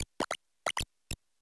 Cri de Famignol Famille de Trois dans Pokémon Écarlate et Violet.